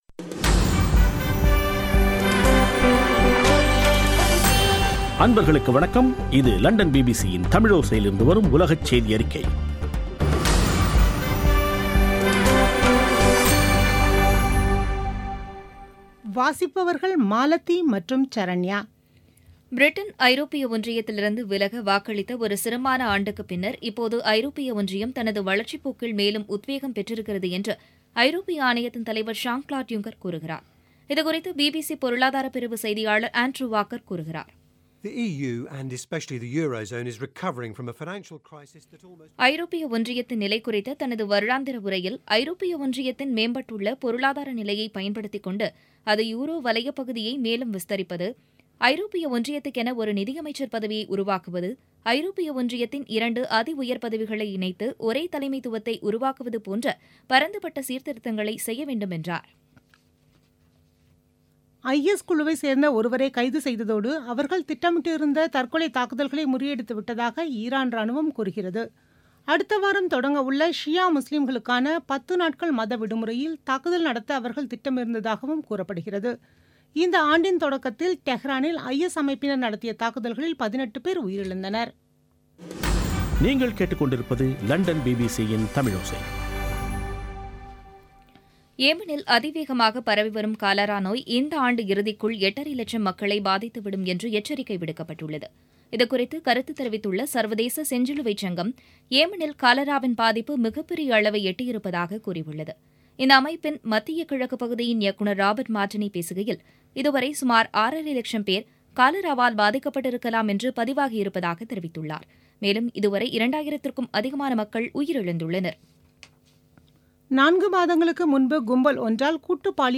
பிபிசி தமிழோசை செய்தியறிக்கை (13/09/2017)